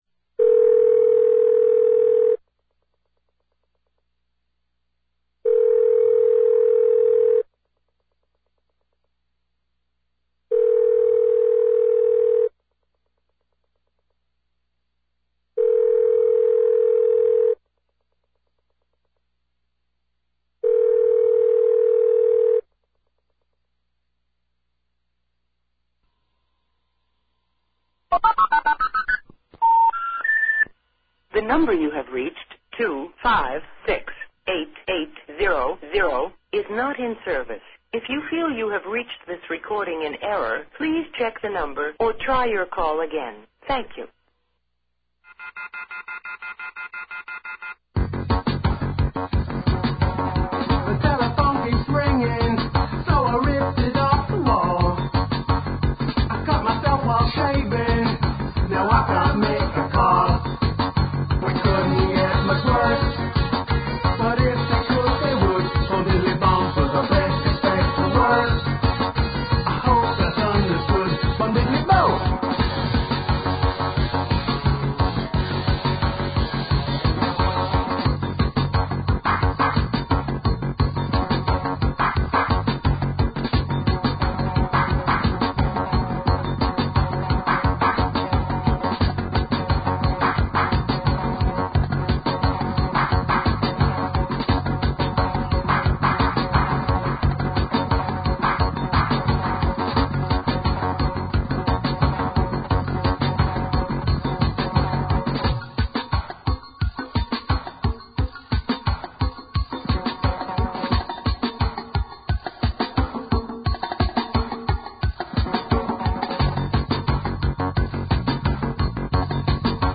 Off The Hook is a weekly show on WBAI radio, New York, produced by 2600 Magazine.